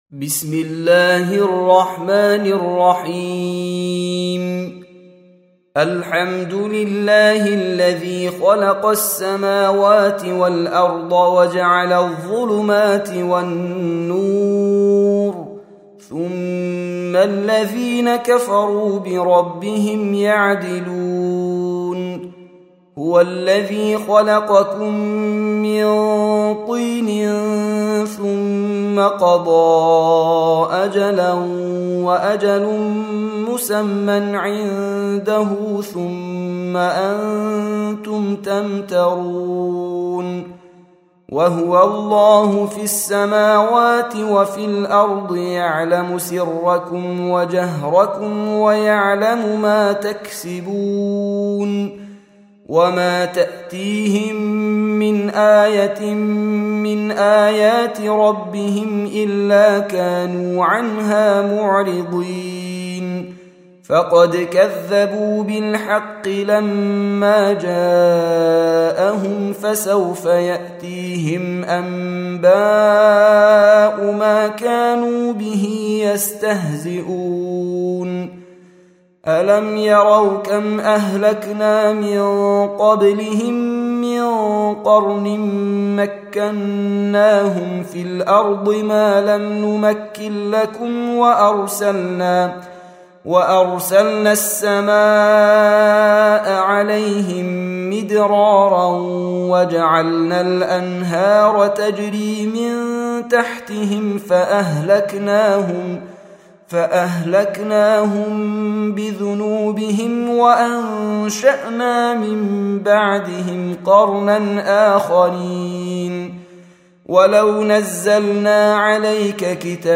6. Surah Al-An'�m سورة الأنعام Audio Quran Tarteel Recitation
Surah Repeating تكرار السورة Download Surah حمّل السورة Reciting Murattalah Audio for 6.